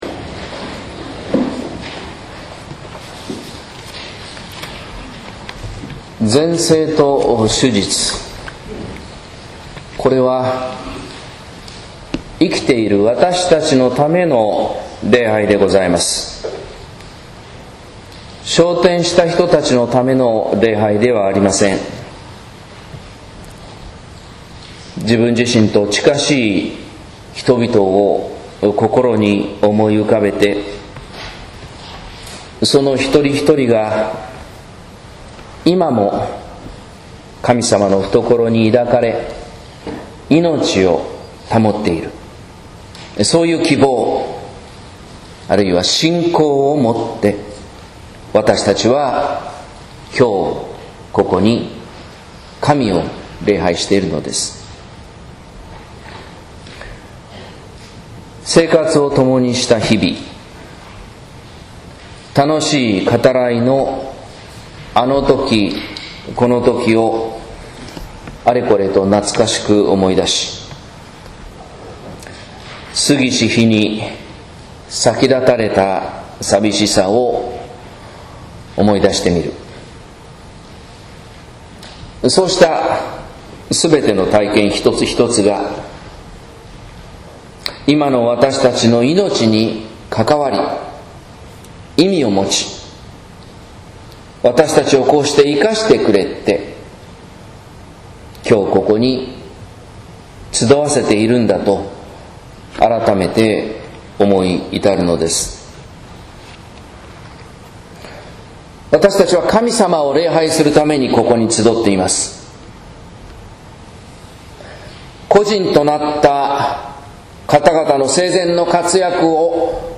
説教「愛でつながる大きないのち」（音声版）